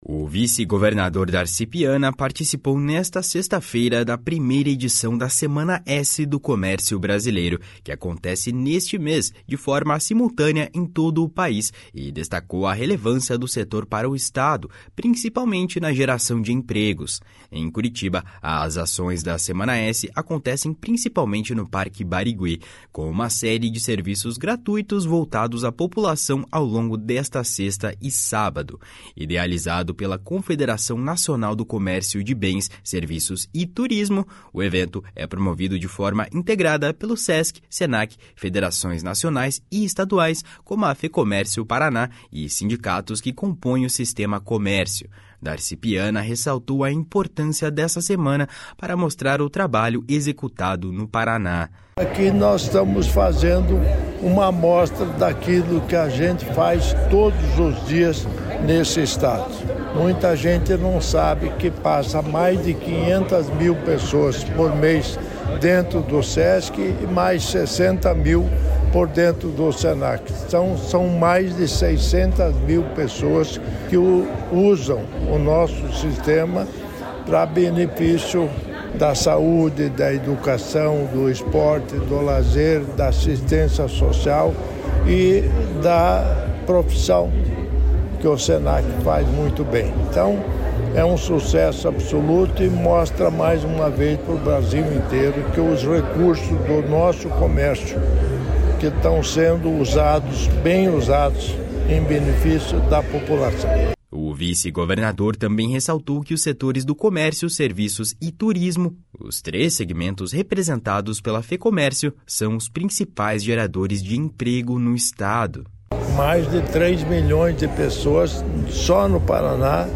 SEMANA S DARCI PIANA.mp3